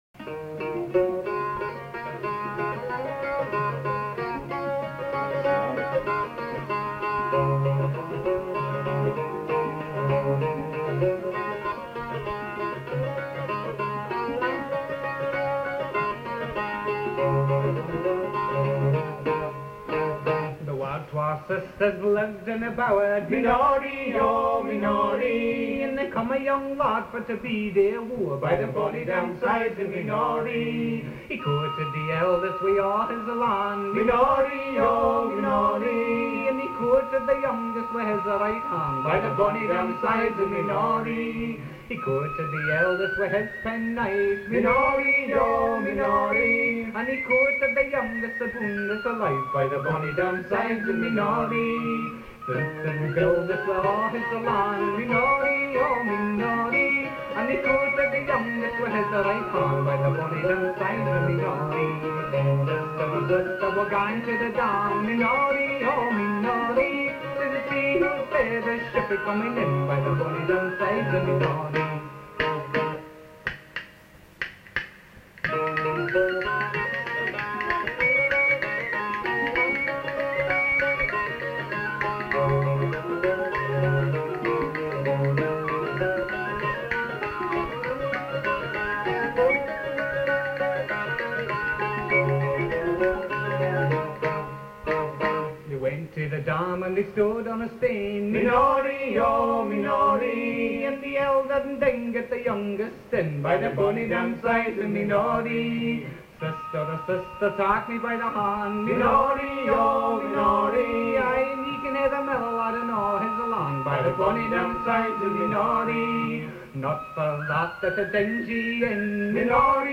The recording quality was probably never very good and has inevitably deteriorated over the years but still gives an idea of the nature of the material and the approach to its performance.
Whistles
Bouzouki
Lead Vocals and Bones
Mandolin